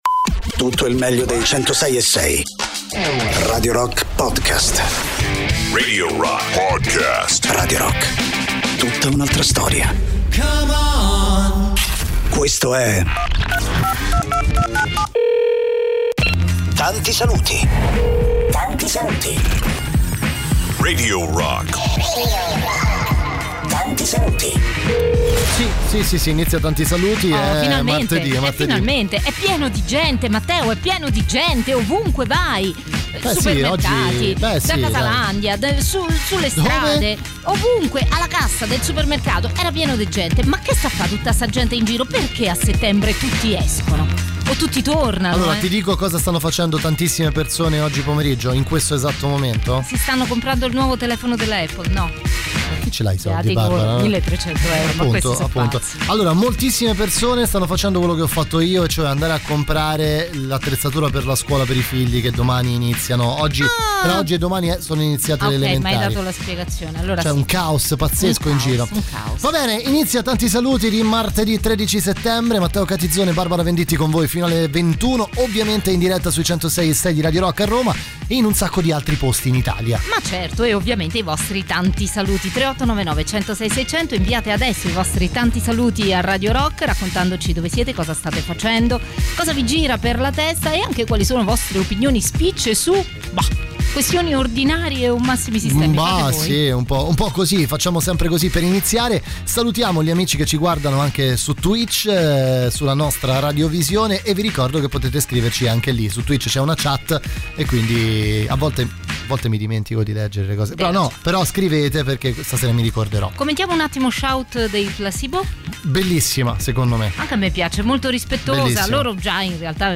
in diretta